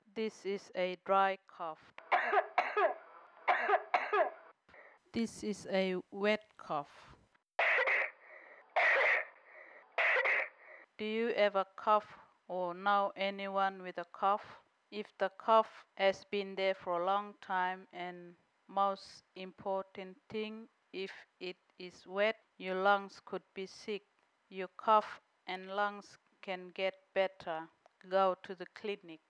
Cough Audio – English
20048_wet-&-dry-cough-english-finalb.wav